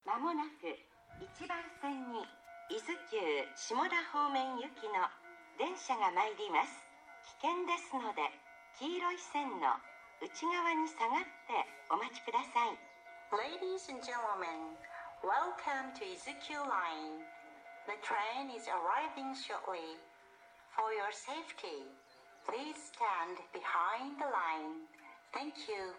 １番線IZ：伊豆急行線
接近放送普通　伊豆急下田行き接近放送です。